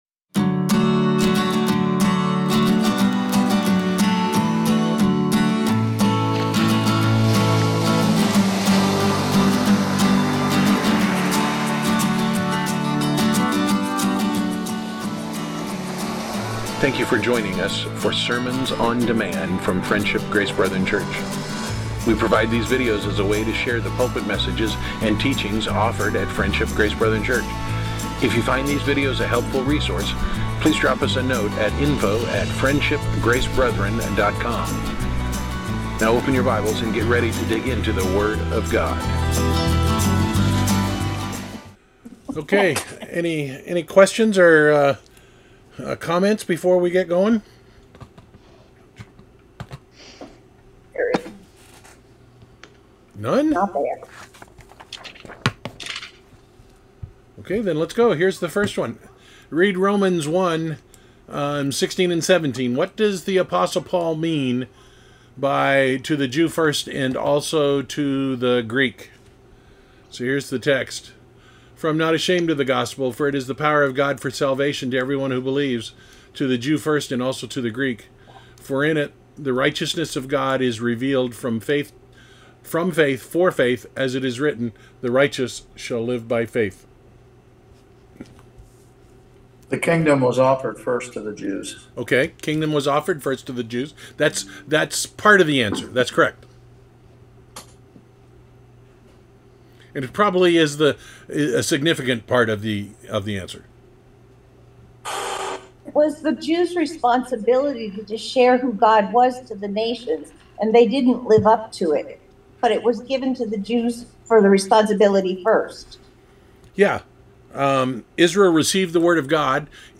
Series: Weekly Bible Discussion